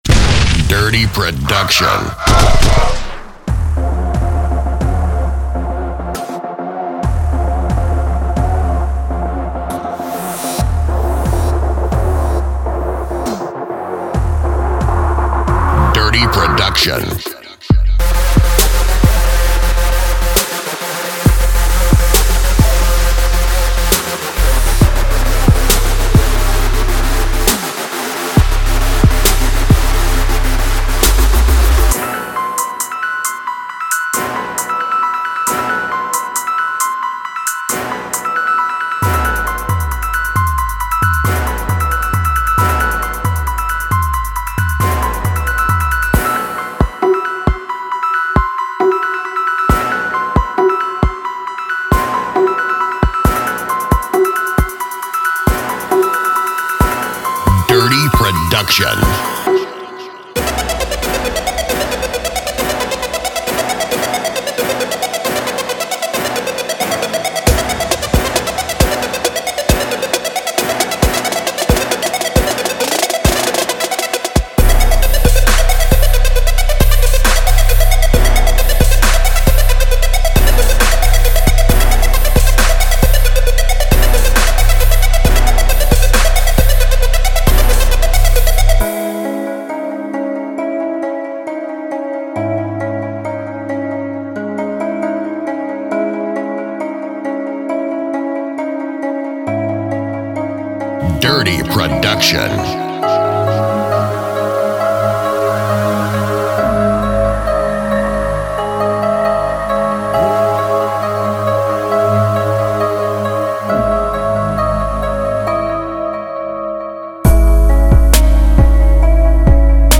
所有套件都装有最新鲜，最独特的声音，包括完美失真的808和各种声音设计技术，以使这款产品脱颖而出。